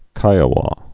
(kīə-wô, -wä, -wā)